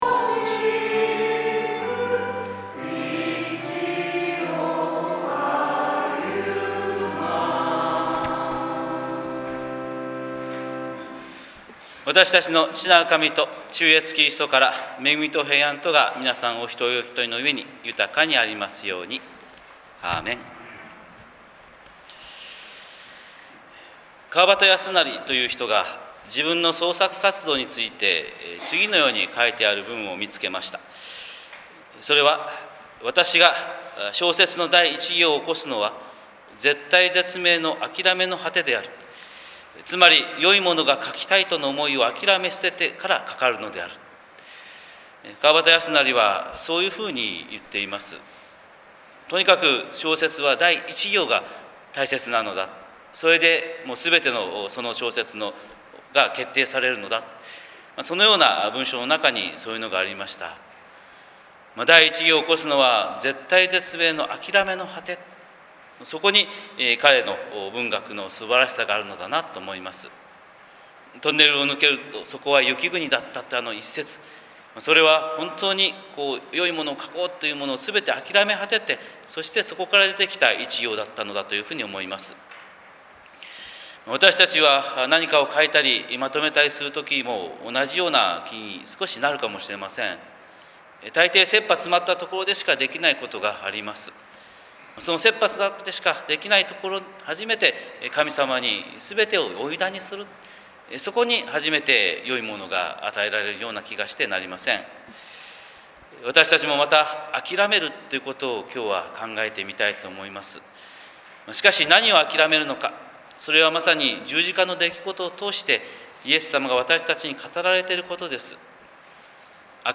神様の色鉛筆（音声説教）